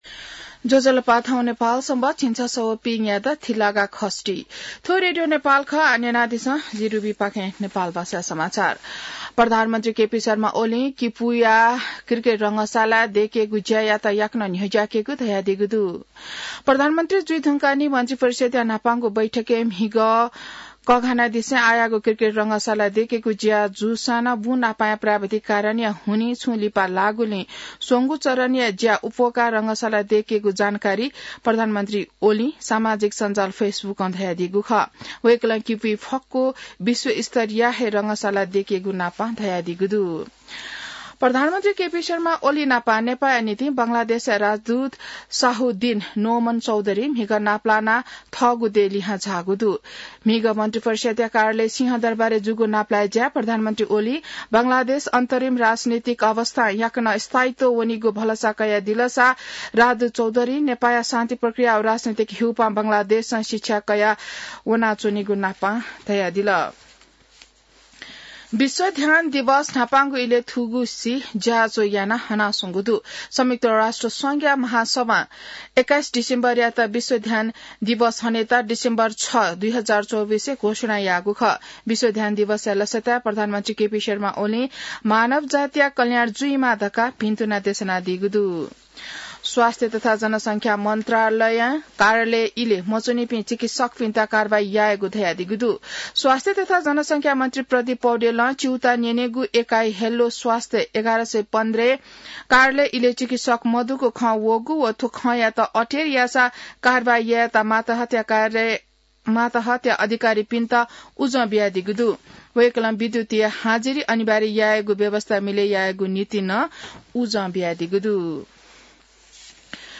नेपाल भाषामा समाचार : ७ पुष , २०८१